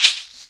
DrShake13.WAV